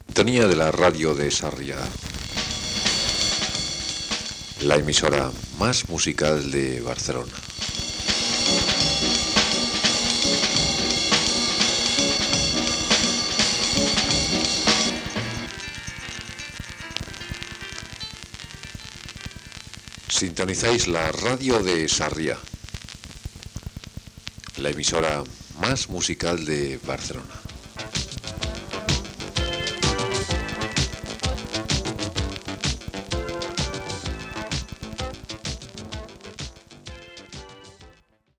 d8d4e5bb1ca70eed704b5e3be3b2c3dabdff3281.mp3 Títol Ràdio Sarrià Emissora Ràdio Sarrià Titularitat Tercer sector Tercer sector Barri o districte Descripció Identificació de l'emissora i tema musical.